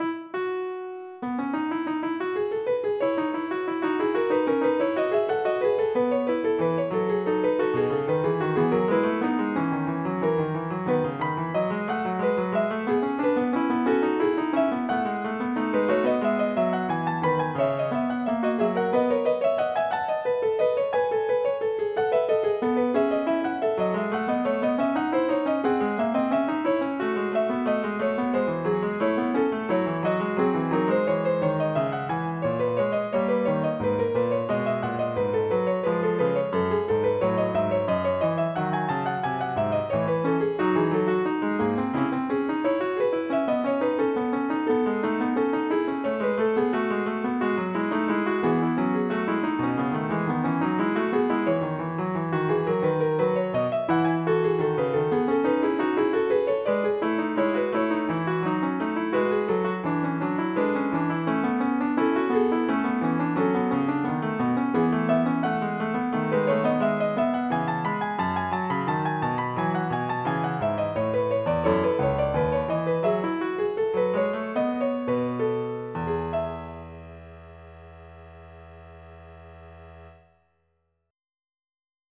MIDI Music File
Type General MIDI
FUGUE.mp3